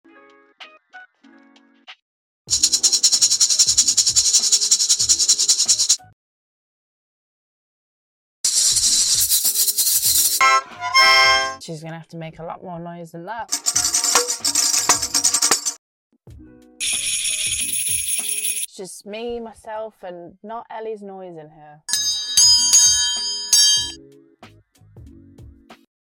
the sound meter stayed on a whisper-quiet 30 decibels
exuberant maracas, lively tambourines and hectic harmonicas